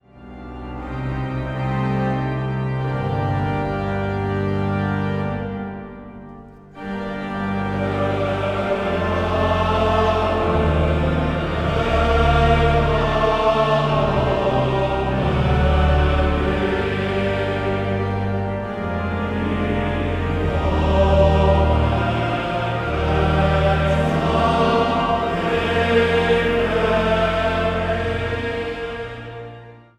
Zang | Mannenzang